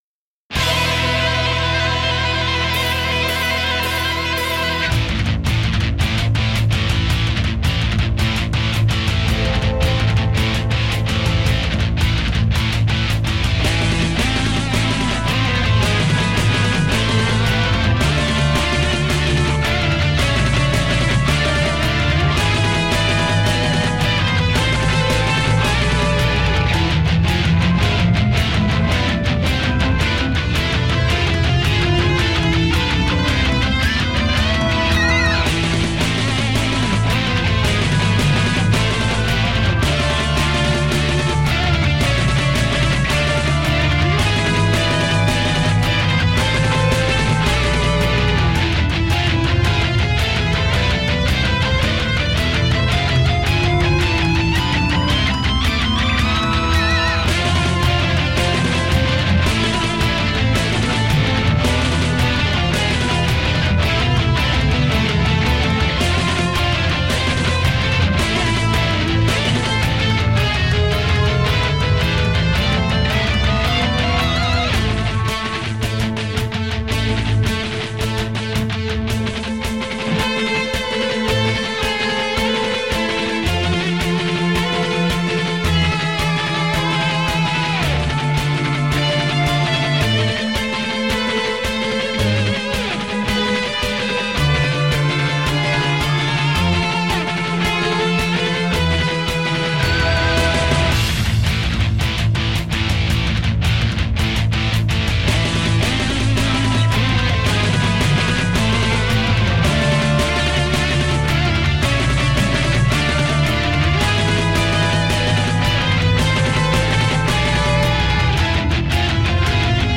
While my guitar gently shreds.
Instrumental progressive metal with a crossover twist!